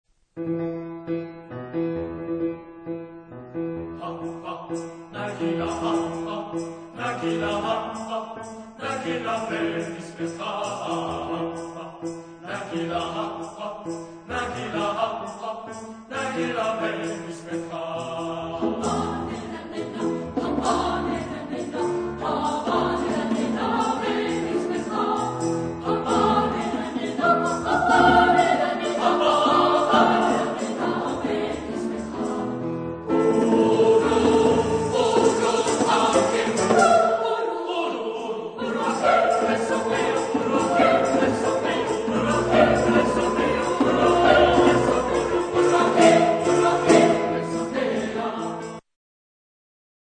Genre-Style-Forme : Populaire ; Danse folkorique ; Profane ; Traditionnel
Caractère de la pièce : festif ; confiant ; dansant
Type de choeur : SATB  (4 voix mixtes )
Instruments : Piano (1)
Tonalité : mi mode de mi
Sources musicologiques : Israeli folk song
interprété par Drakensberg Boys' Choir